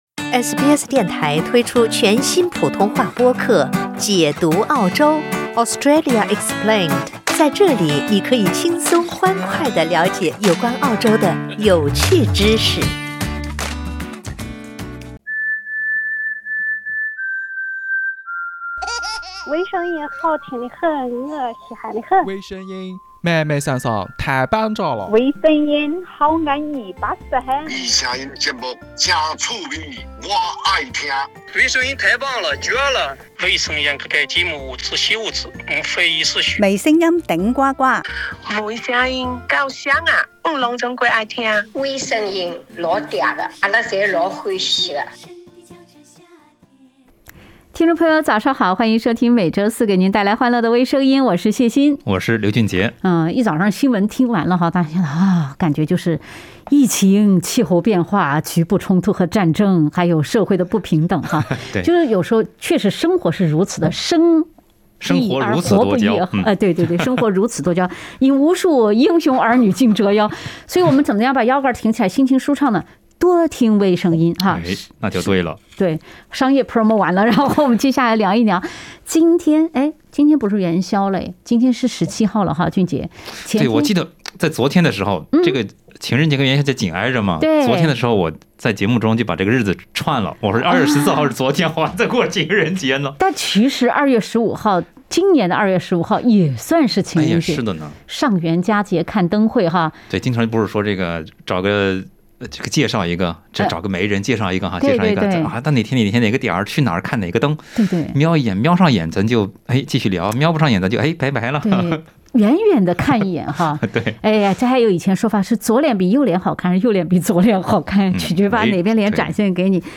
（点击封面图片，收听风趣对话）